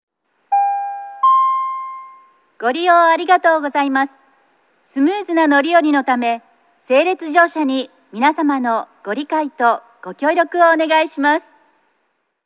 大阪メトロ(大阪市営地下鉄)の啓発放送